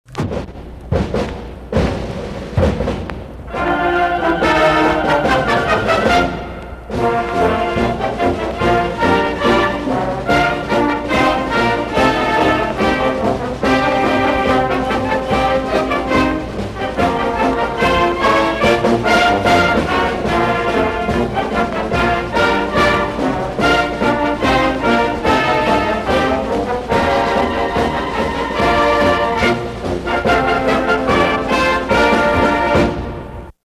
This is an old version of the Ben Davis High School Fight Song from the 1960's played by the Marching Giants in, we believe, 1963 or 1968. It was taken from a vinyl record several years ago and digitized.